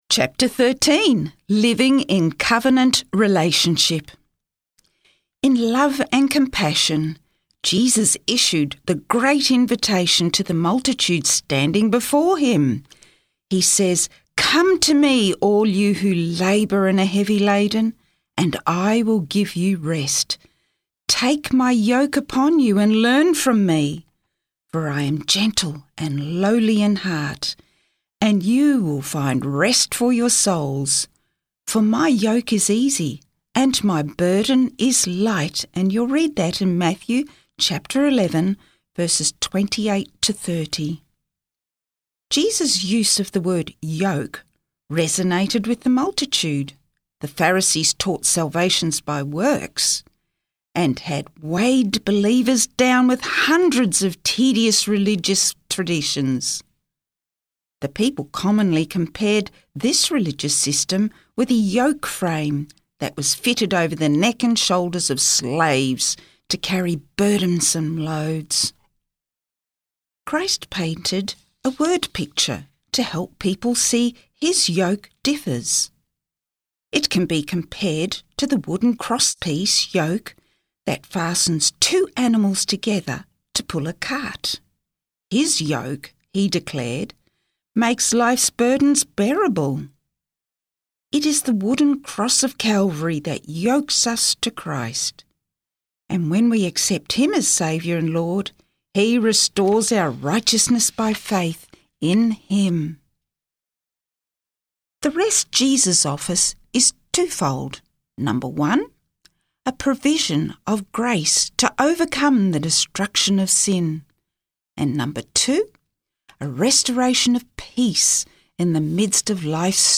Book Reading - Spotless